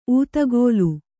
♪ ūtagōlu